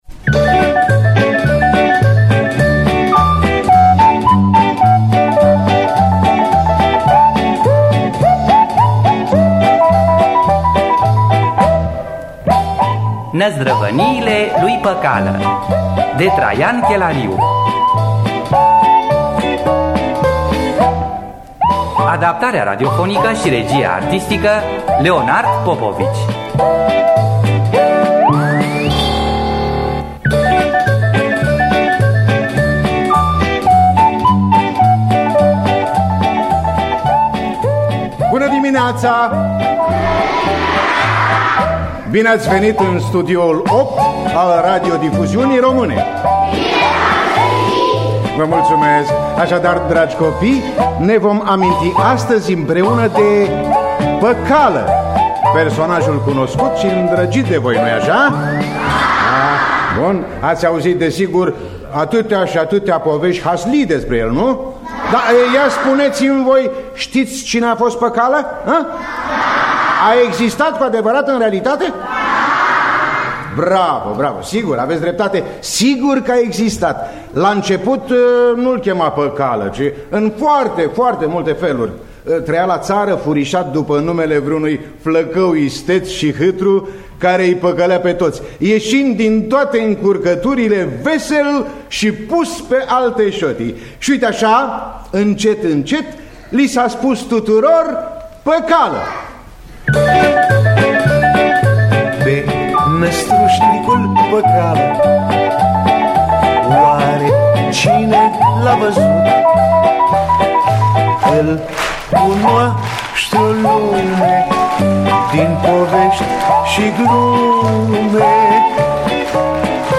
„Năzdrăvăniile lui Păcală”, scenariu de Traian Chelariu – Teatru Radiofonic Online